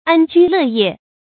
注音：ㄢ ㄐㄨ ㄌㄜˋ ㄧㄜˋ
安居樂業的讀法